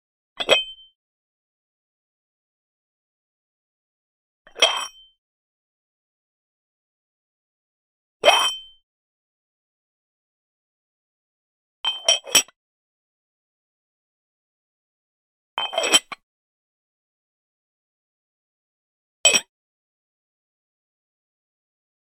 Ceramic Sugar Pot Remove Lid Sound
household
Ceramic Sugar Pot Remove Lid